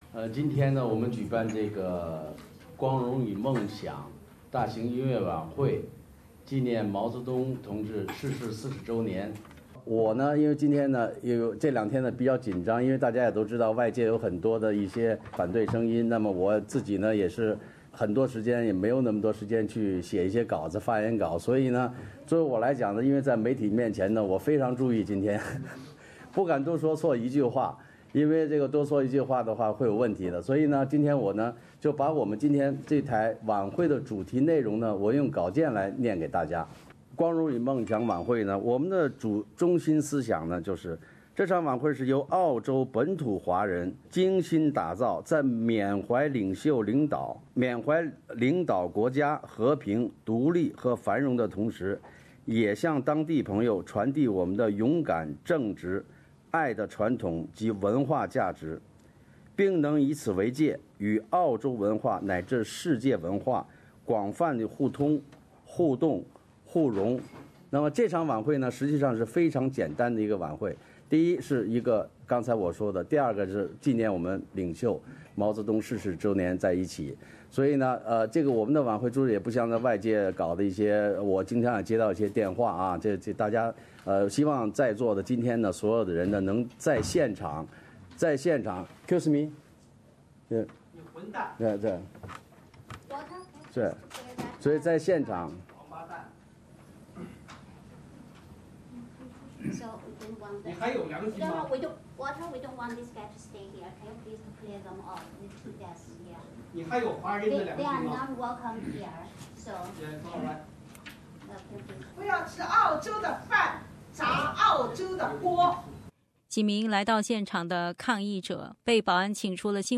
昨天上午，主办者在悉尼举行纪念毛泽东音乐晚会媒体发布会的现场，被抗议者几次打断。其中一名抗议者高喊："不要吃澳洲的饭，砸澳洲的锅"。